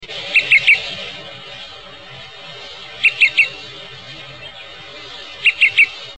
a. A trebled
pip-pip-pip (P,R), example 2.
Also given in two's (L).